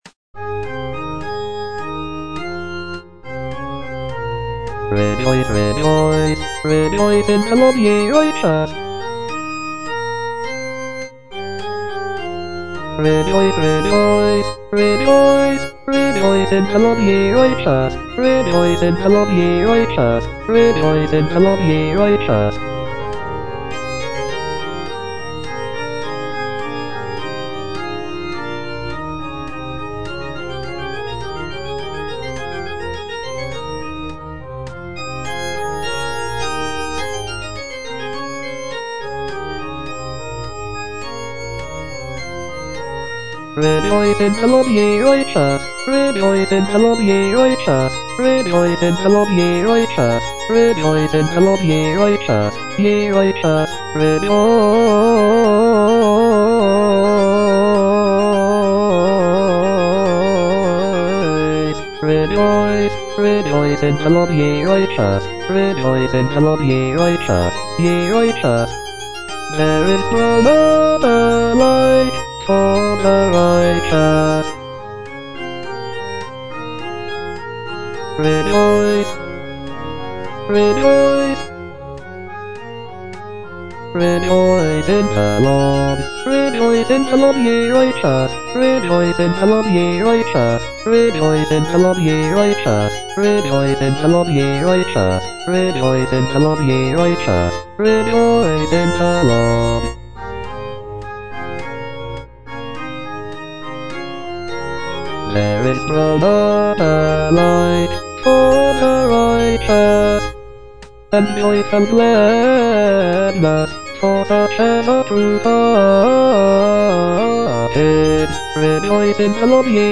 (A = 415 Hz)
Bass (Voice with metronome) Ads stop